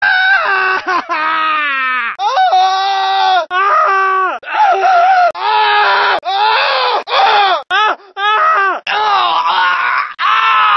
gta-san-andreas-pedestrian-voices-coughing-pain-male-audiotrimmer_NVtZKmE.mp3